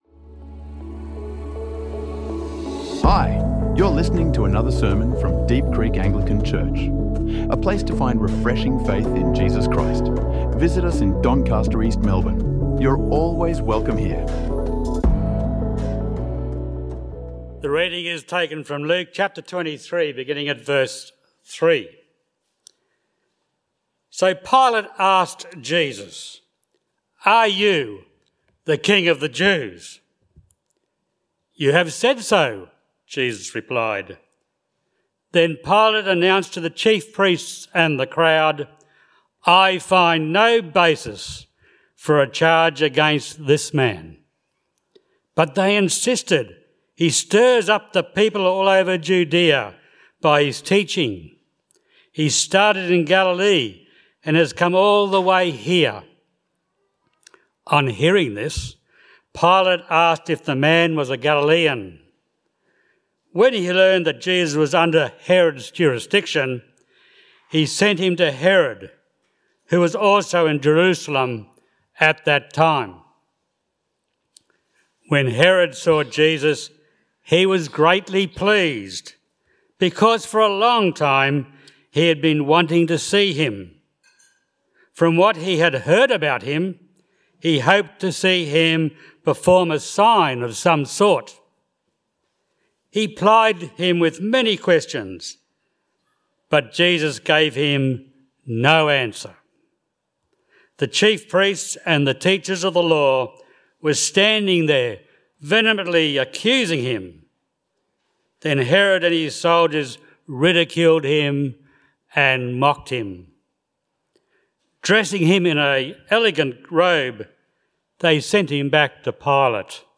Good Friday: The Darkness | Sermons | Deep Creek Anglican Church